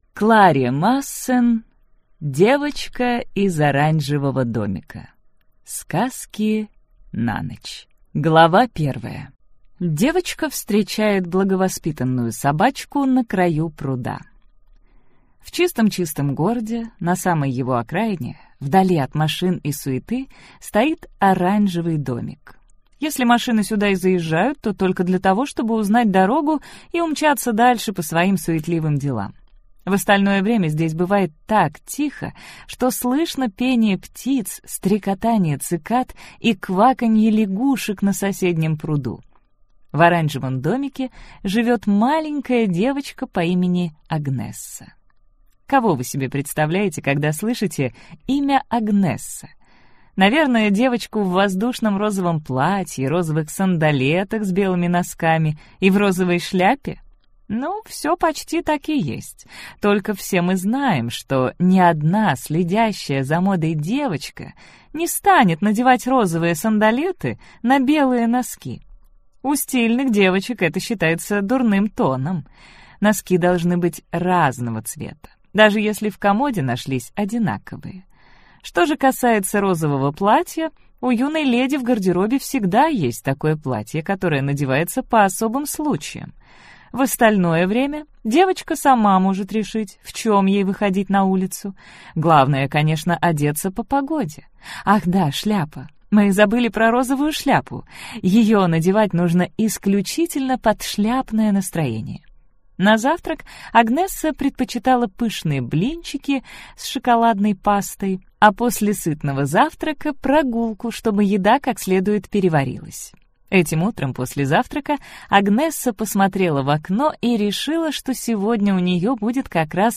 Аудиокнига Девочка из Оранжевого домика.